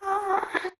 moan2.ogg